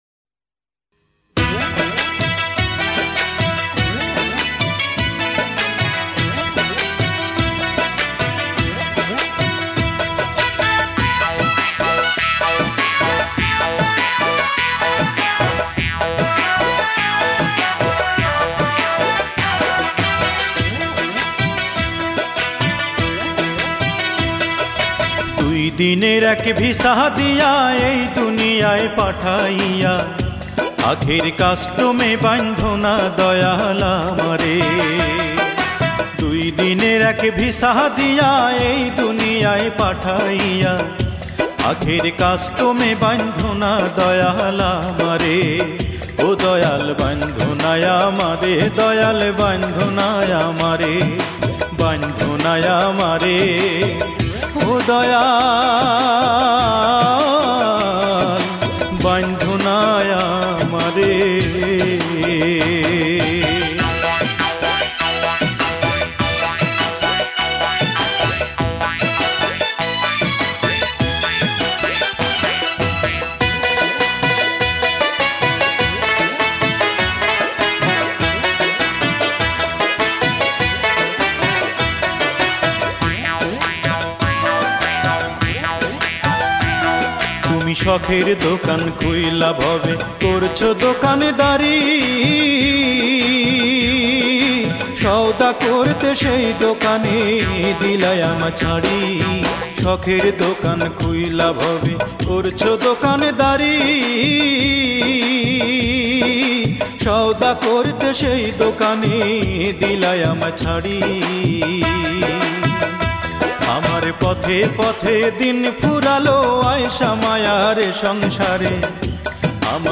Genre Adhunik Bangla